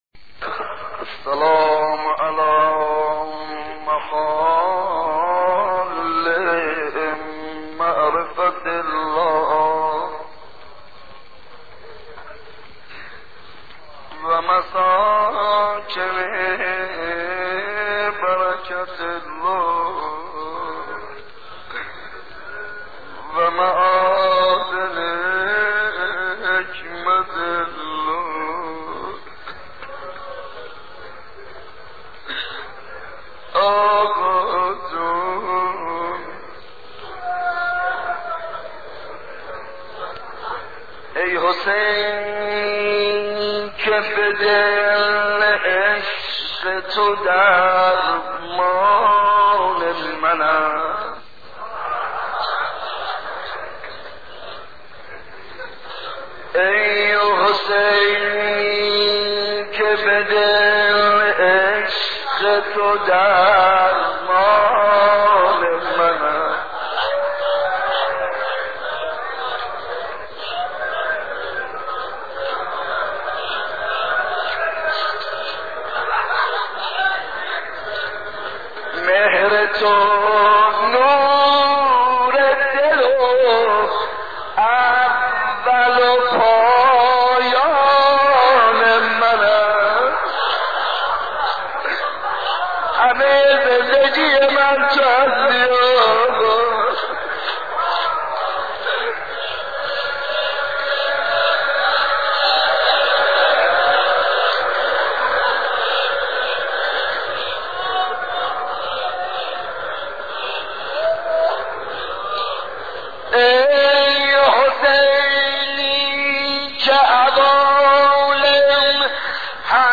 صوت/ مداحی حاج منصور ارضی در سال 63
برچسب ها: شهدای ایران ، صورت ، مداحی ، حاج منصور ، منصور ارضی